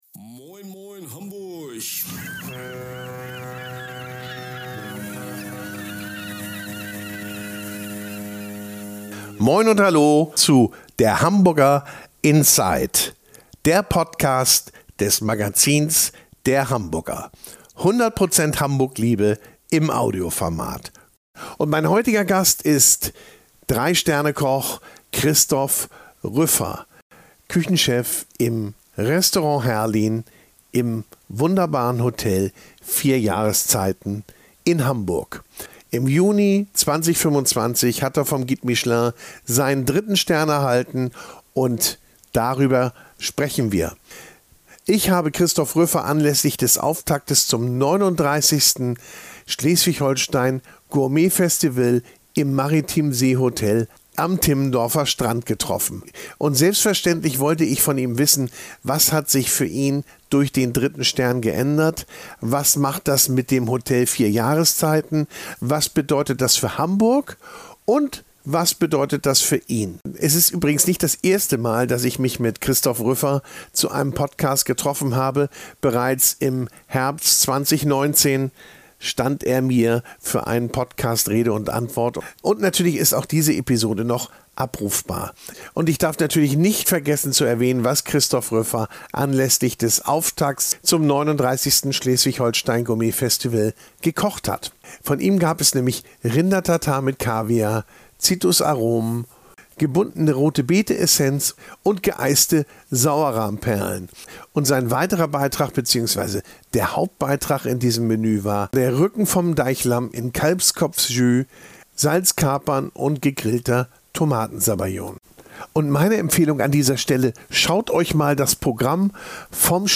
Ein Gespräch über Anspruch, Erwartung und die Kunst, große Küche mit Besonnenheit weiterzudenken.